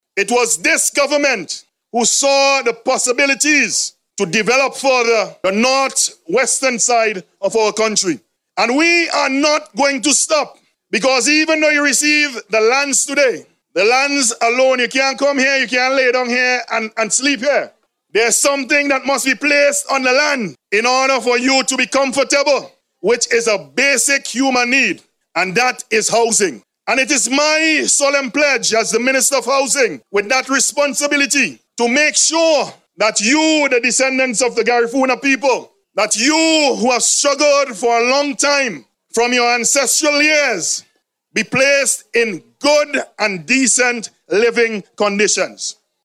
He made this statement during a recent groundbreaking ceremony for a sporting facility at Langley Park in Georgetown.